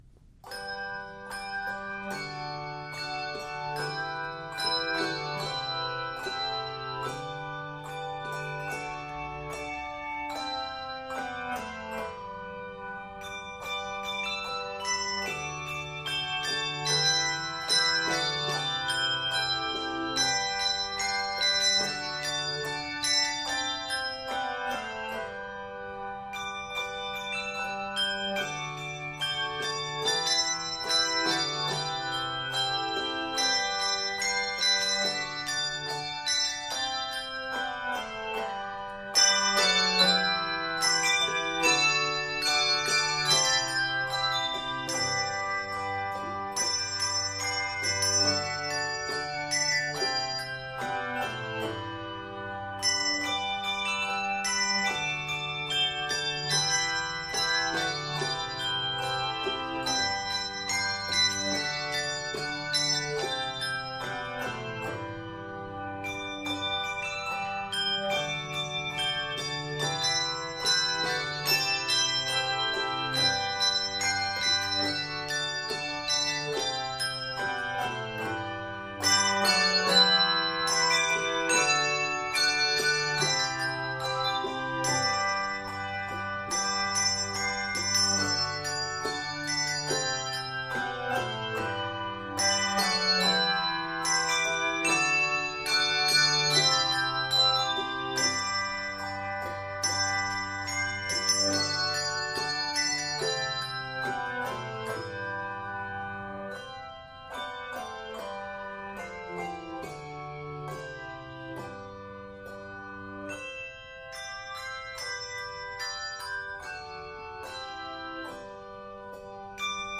handbells